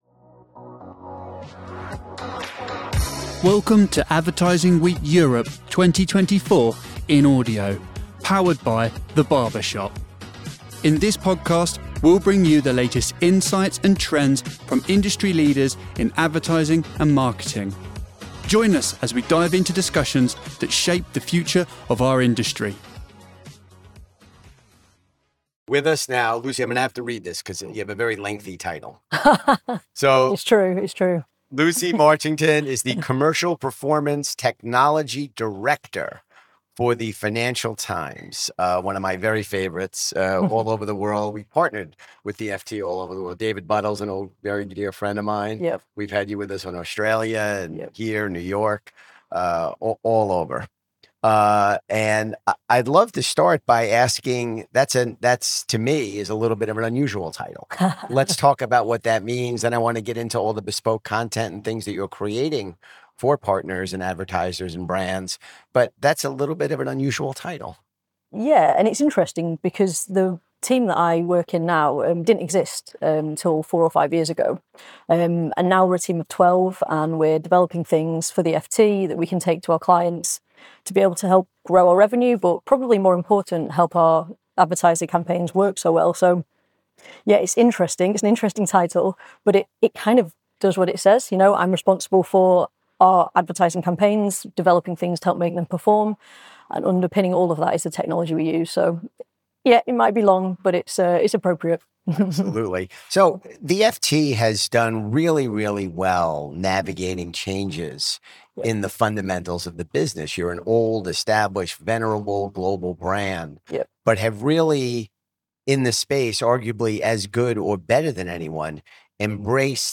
This session provides insights into creating impactful, customised marketing strategies that drive results.